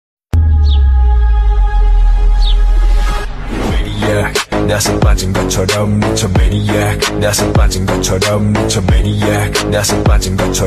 Heavy bass, elegant and dynamic